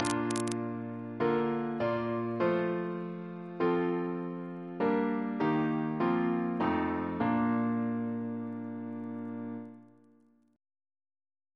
Single chant in B♭ Composer: F. A. Gore Ouseley (1825-1889) Reference psalters: ACB: 45; ACP: 1; CWP: 208; OCB: 12; PP/SNCB: 185; RSCM: 201